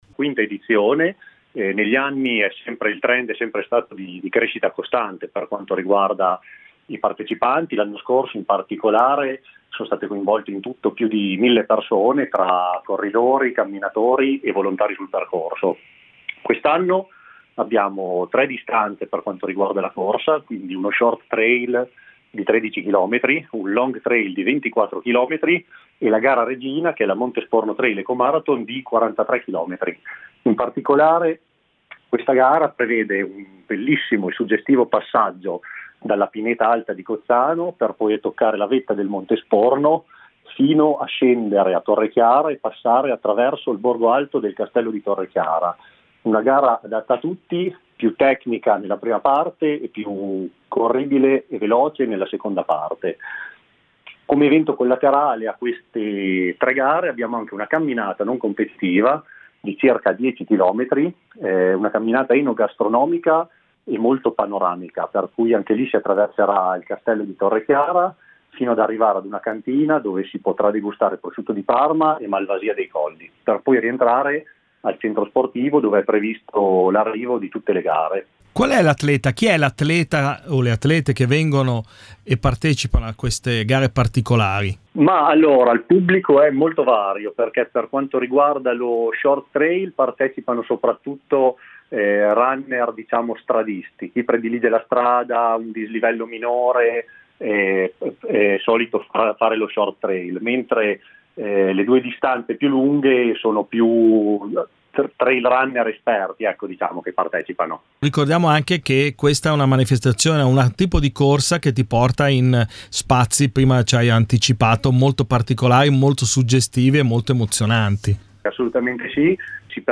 ascolta l’intervista con LUCA CANETTI assessore allo sport del Comune di Langhirano (PR)